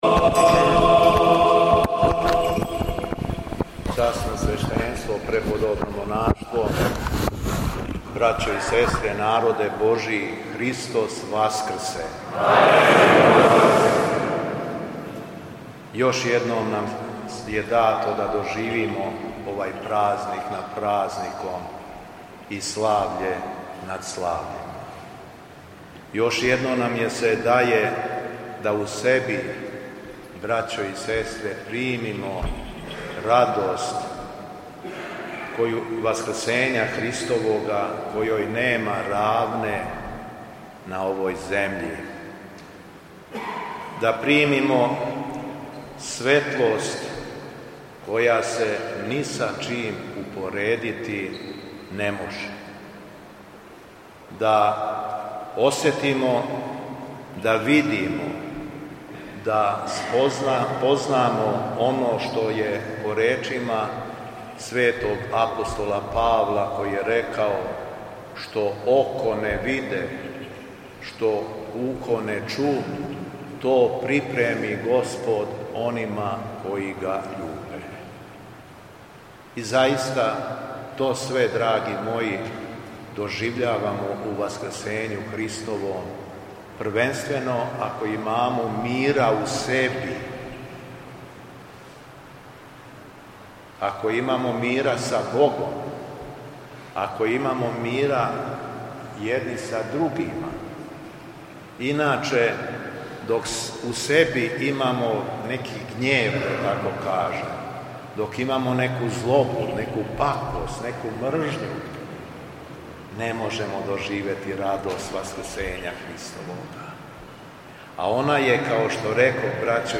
Беседа Његовог Високопреосвештенства Митрополита шумадијског г. Јована
Његово Високопреосвештенство Митрополит шумадијски Г. Јован служио је Свету Архијерејску Литургију у храму Светог великомученика Георгија на Опленцу.